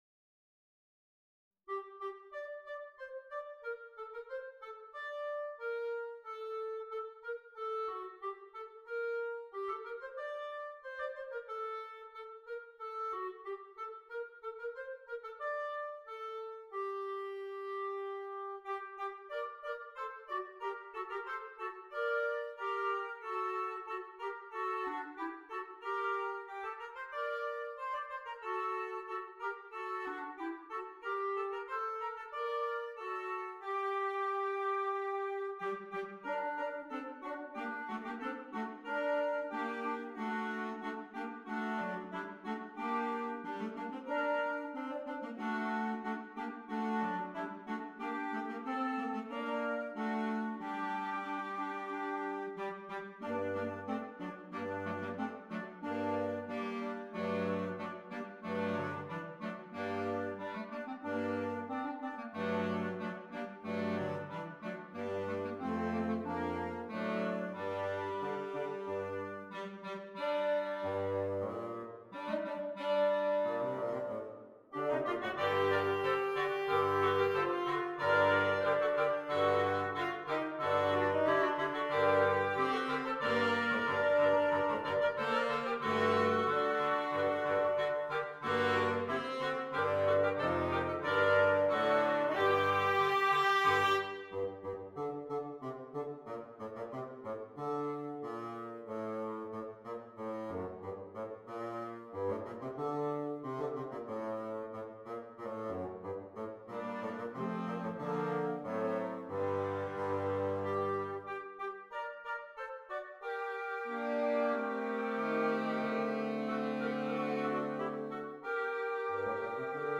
Christmas
Interchangeable Woodwind Ensemble
Traditional